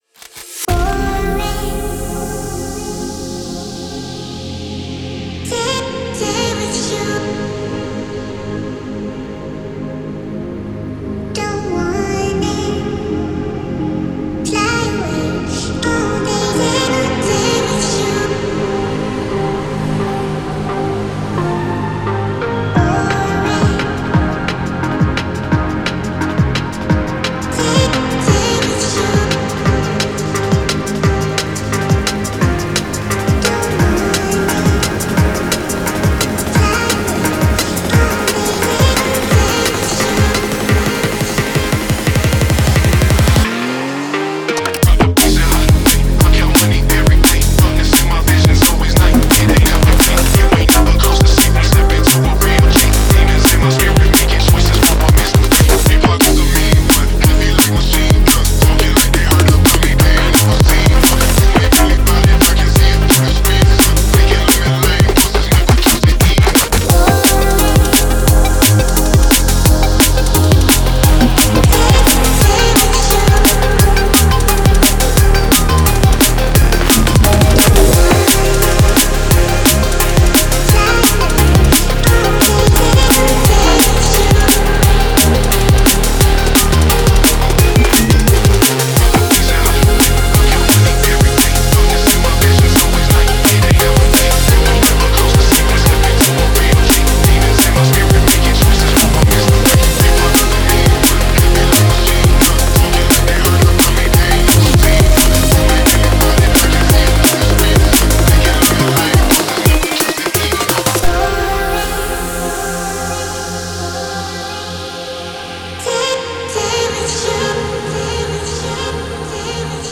Liquid, Dreamy, Euphoric, Floating, Mysterious